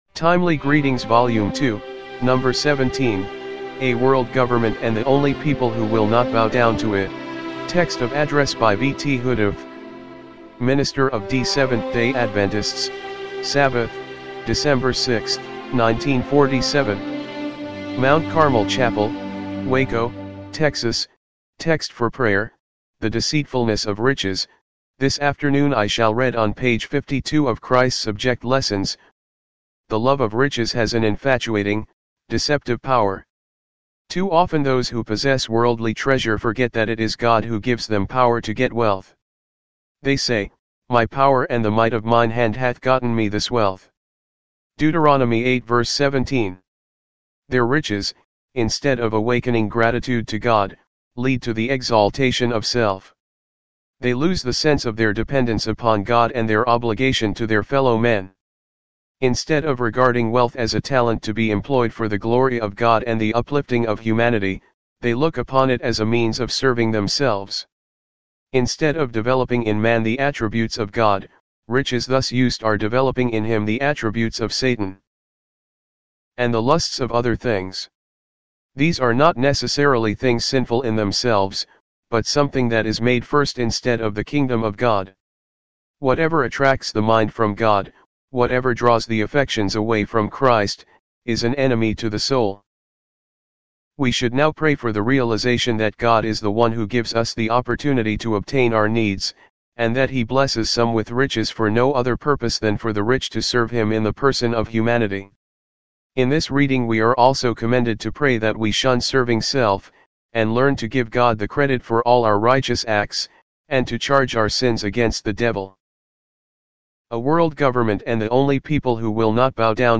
timely-greetings-volume-2-no.-17-mono-mp3.mp3